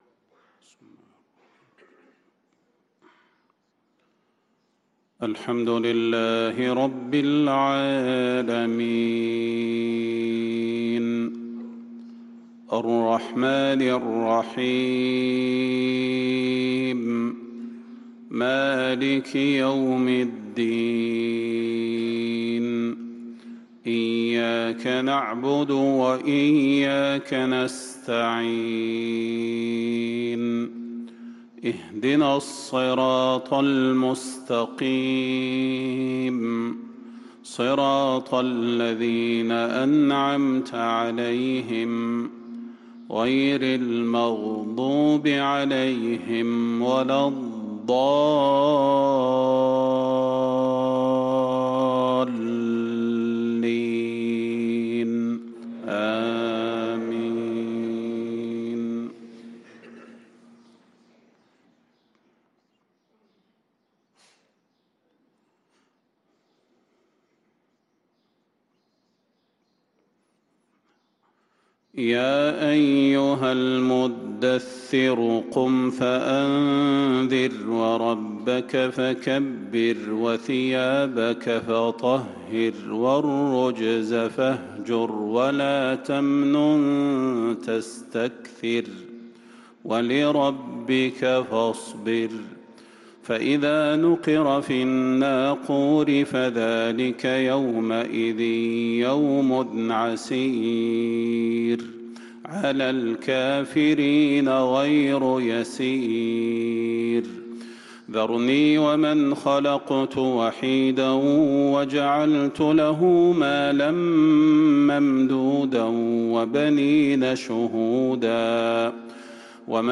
صلاة الفجر للقارئ صلاح البدير 21 رجب 1445 هـ
تِلَاوَات الْحَرَمَيْن .